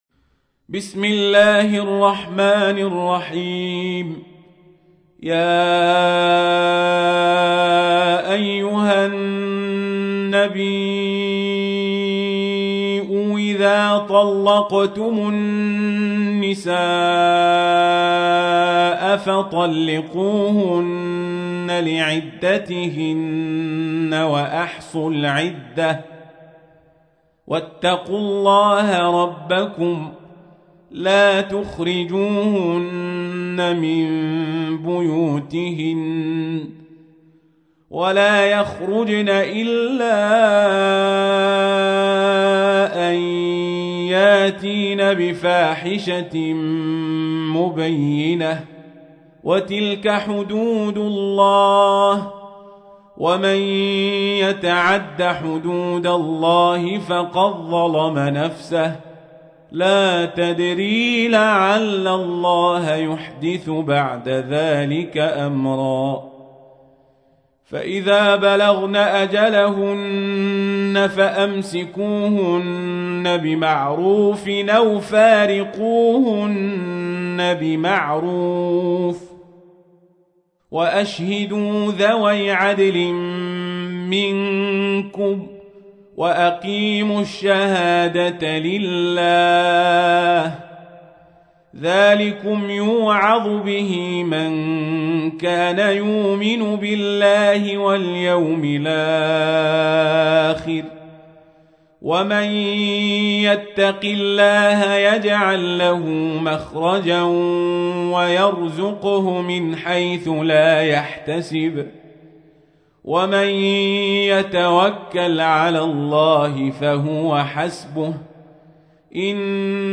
تحميل : 65. سورة الطلاق / القارئ القزابري / القرآن الكريم / موقع يا حسين